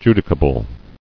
[ju·di·ca·ble]